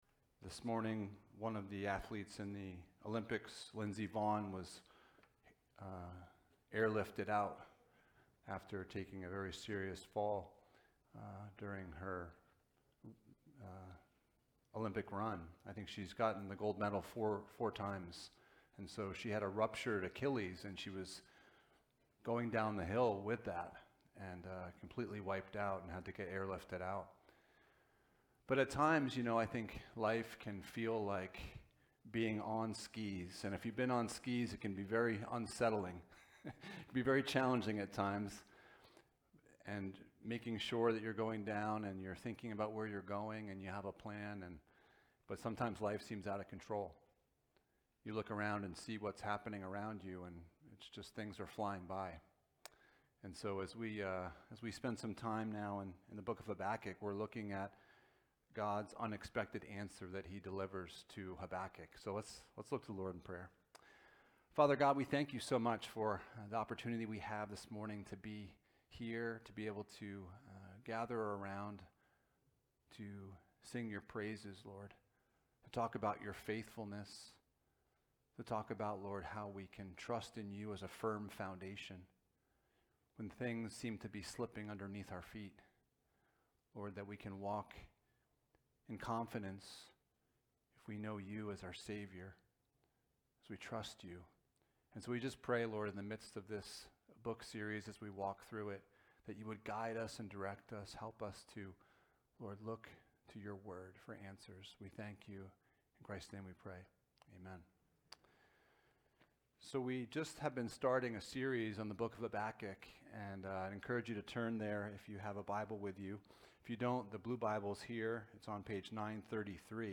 Passage: Habakkuk 1: 5-11 Service Type: Sunday Morning